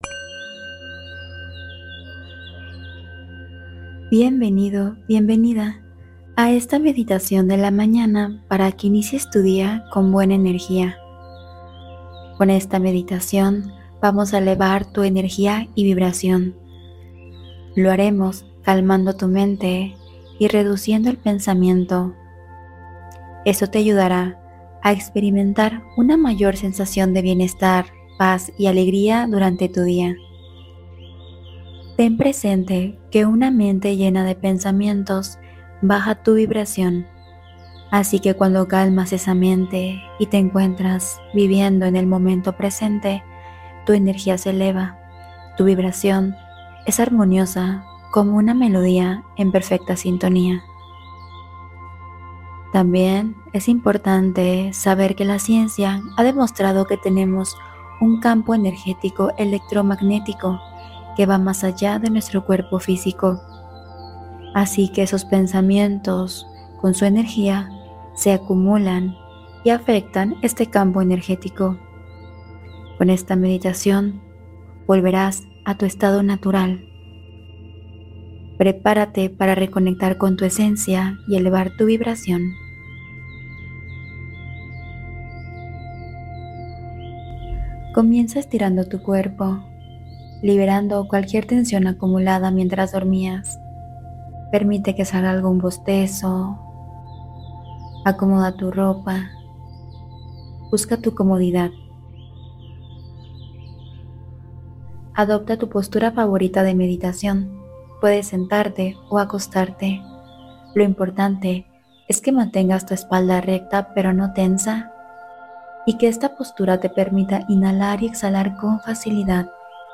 Relaja la Mente y Recupera Claridad: Meditación Profunda Guiada